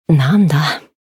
灵魂潮汐-迦瓦娜-互动-不耐烦的反馈1.ogg